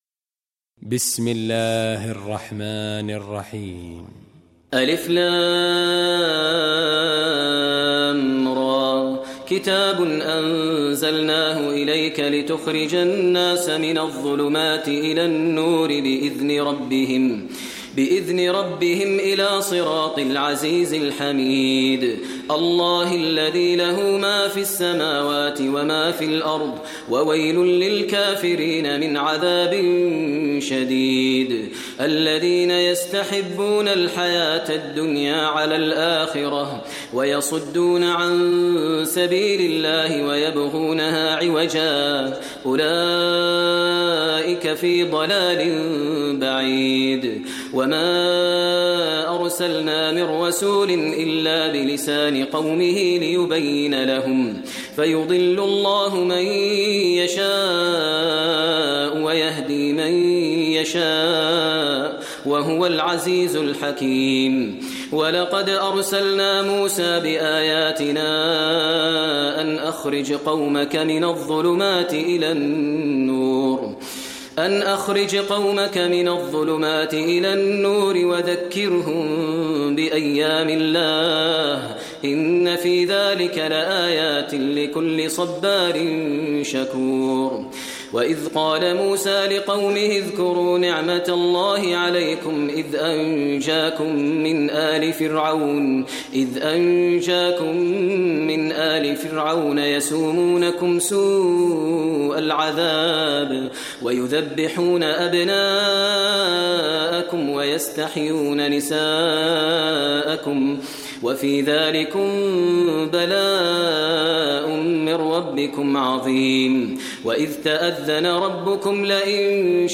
Surah Ibrahim Recitation by Maher al Mueaqly
Surah Ibrahim, listen online mp3 tilawat / recitation in Arabic, recited by Imam e Kaaba Sheikh Maher al Mueaqly.